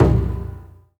A#3 BUZZH0AL.wav